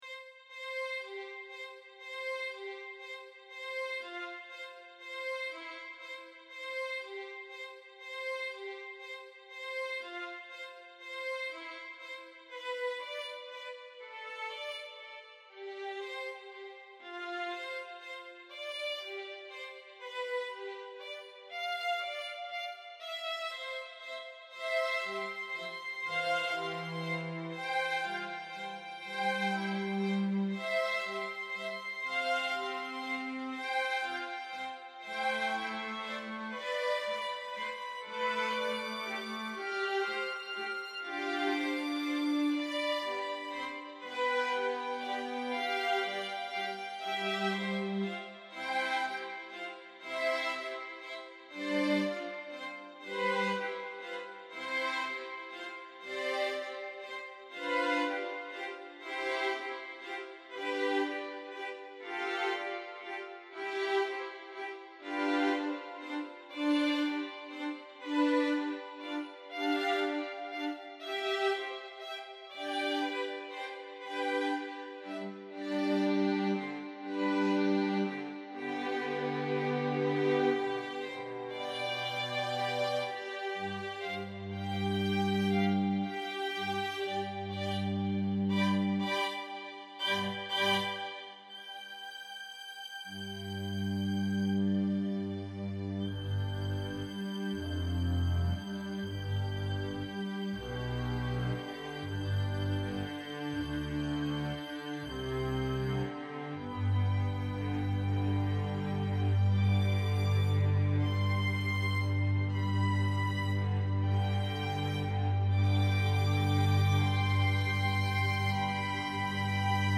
Instrumental.